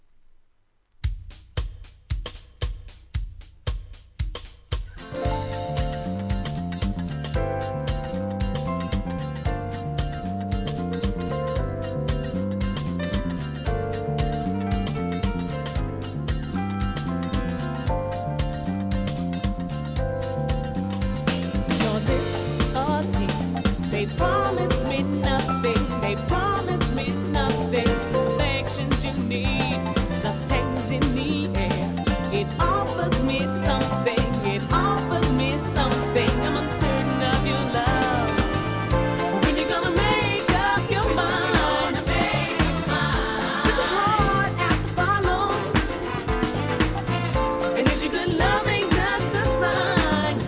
1995年人気ACID JAZZ!